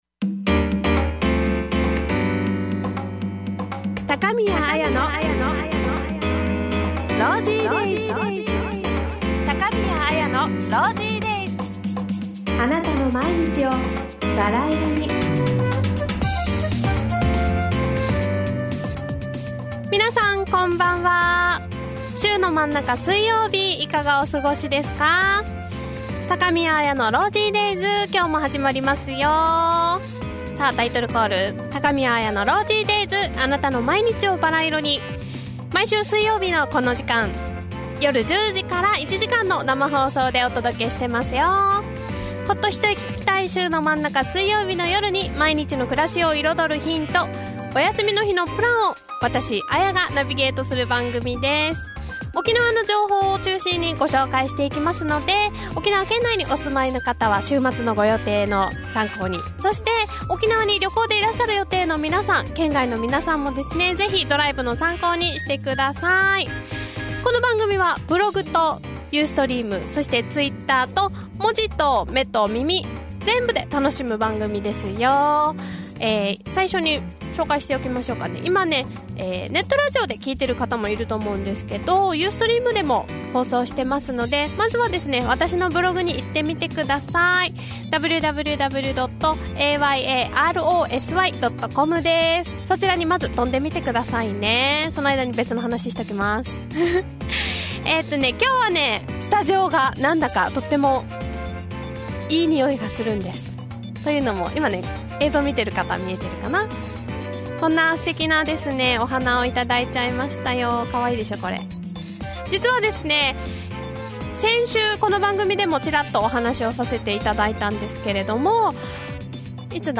毎週水曜日２２時から１時間の生放送